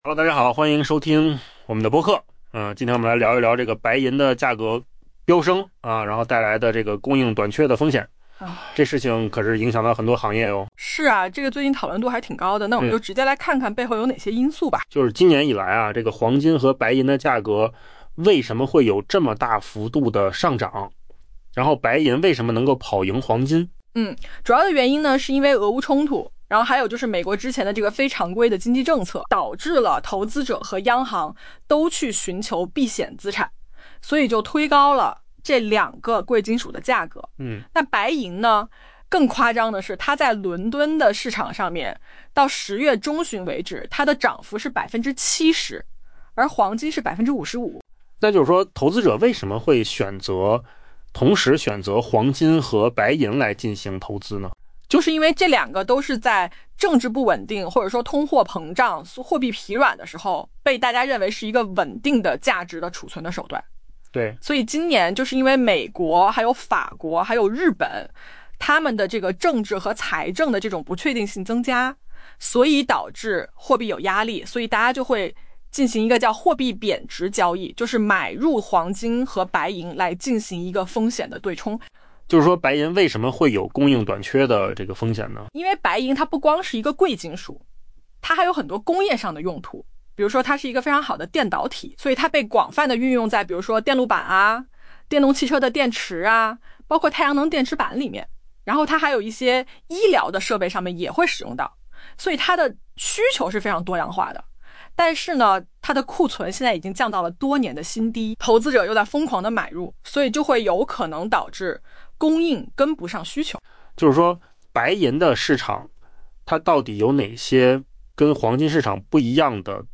AI 播客：换个方式听新闻 下载 mp3 音频由扣子空间生成 今年以来，由于俄乌冲突以及美国特朗普政府非传统的经济政策，投资者和各国央行纷纷涌向避险资产，推动黄金价格大幅上涨。